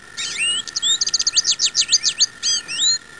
American Goldfinch
Scientific Name: Carduelis tristis
agoldfinch.wav